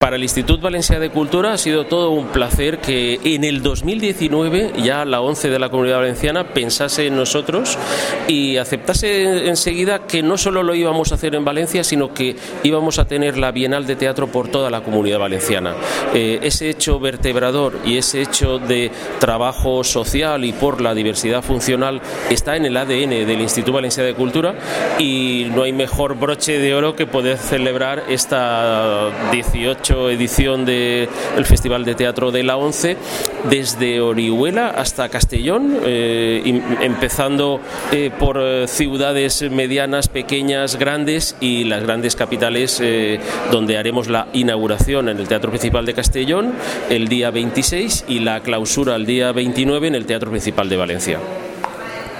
Un momento de la presentación